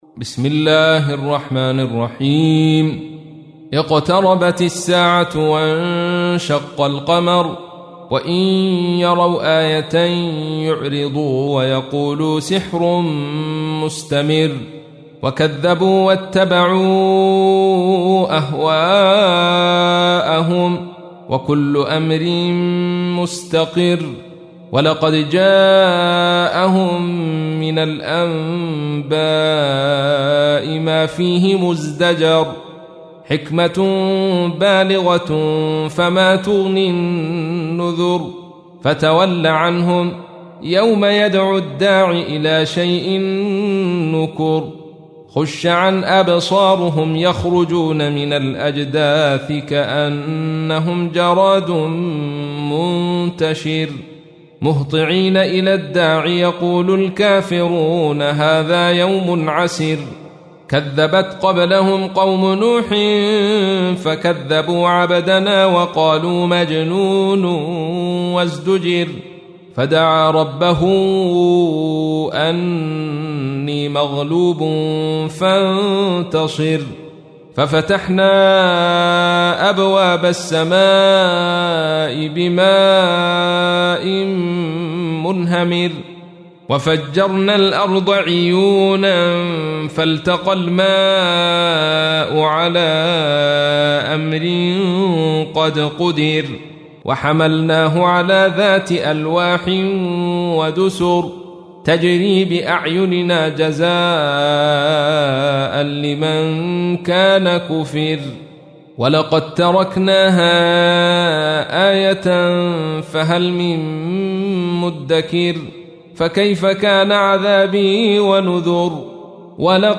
تحميل : 54. سورة القمر / القارئ عبد الرشيد صوفي / القرآن الكريم / موقع يا حسين